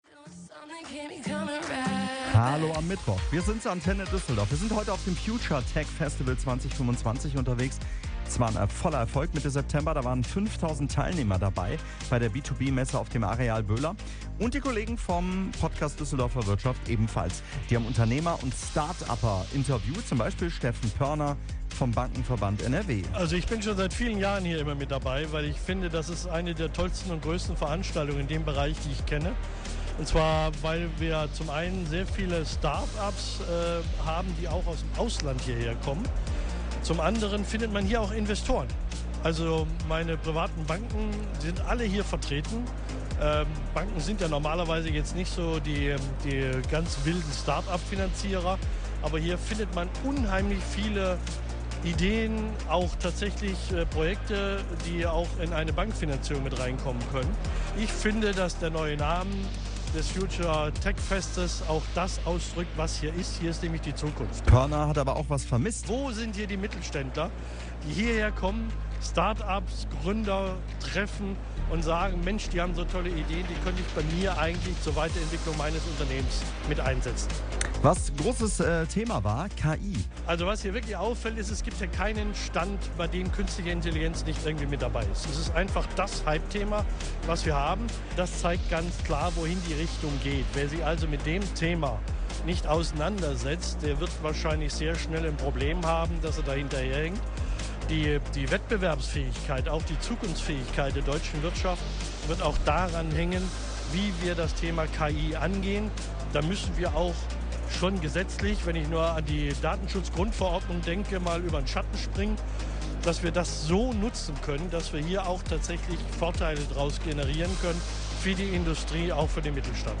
Mit exklusiven Messeführungen für unsere Mitgliedsunternehmen – die begehrten Tickets waren innerhalb weniger Stunden vergriffen – und mit einer Sonderfolge unseres Podcasts „Düsseldorfer Wirtschaft“ live vom Festivalgelände.